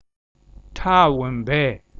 Retroflex
Hta.wum-bei  {HTa.wum:bè:} <))